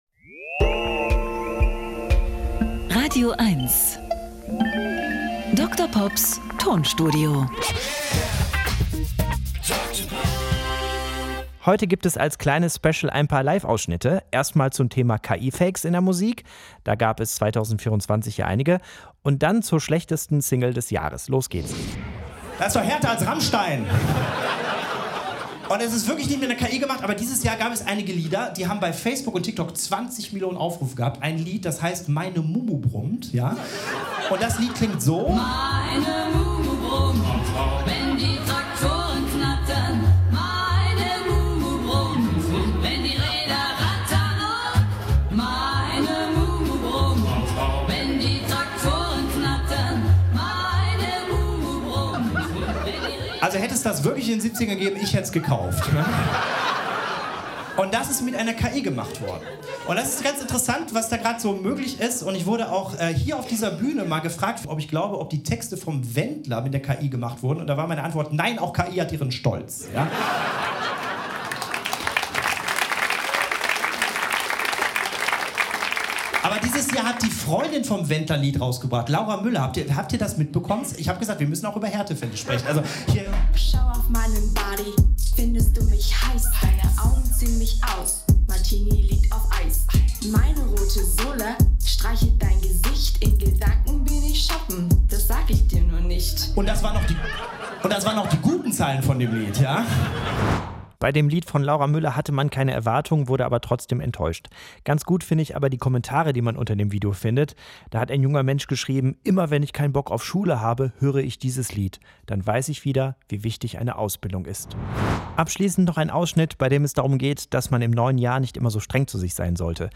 1 Neujahrsspecial mit Live-Mitschnitten 3:46